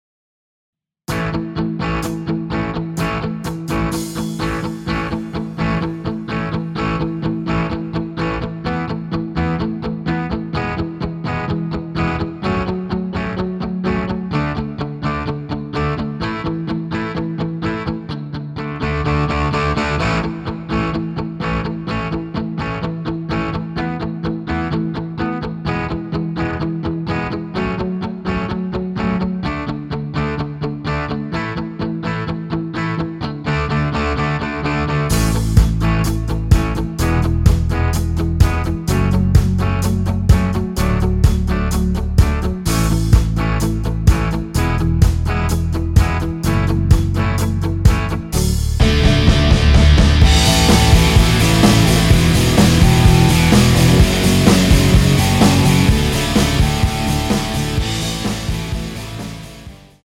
전주없이 노래 시작 하는 곡이라 전주 만들어 놓았습니다.
(하이 햇 소리 끝나고 노래 시작 하시면 됩니다.)(멜로디 MR 미리듣기 확인)
원키에서(-2)내린 MR입니다.
앞부분30초, 뒷부분30초씩 편집해서 올려 드리고 있습니다.